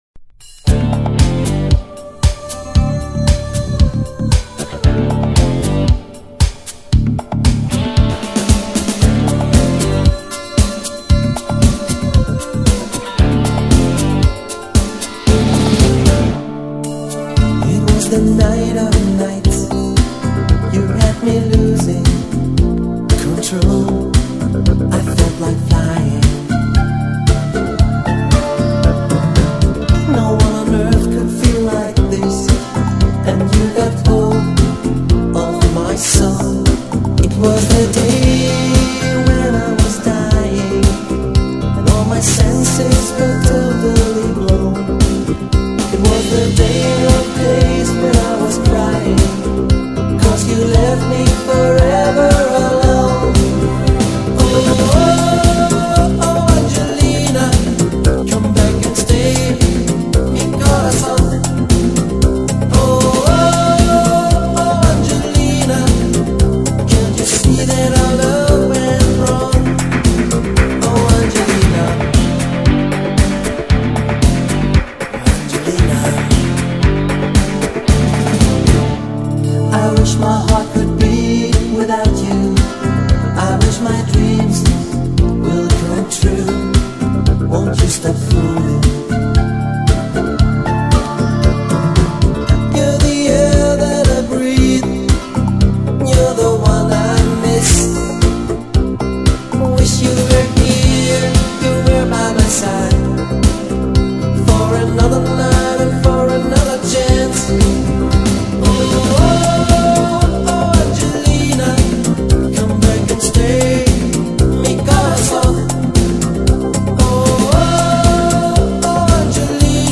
В ней очень много так называемых сибилянтов.